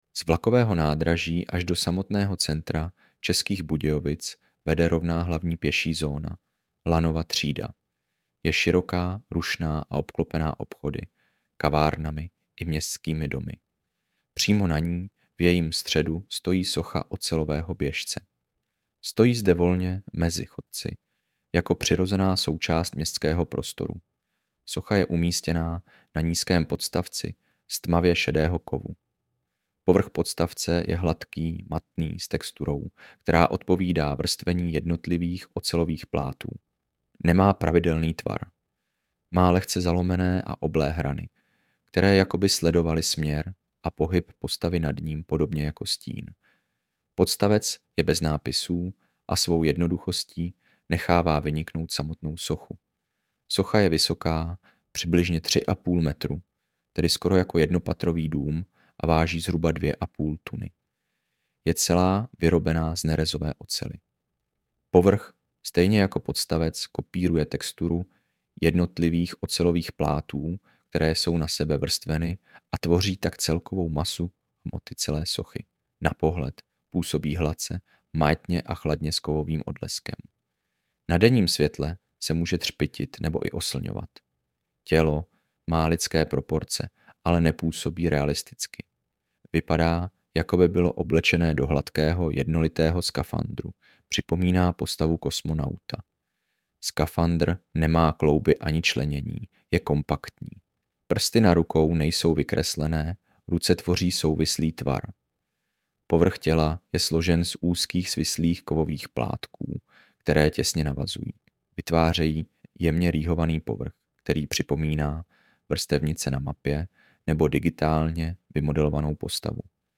AUDIOPOPIS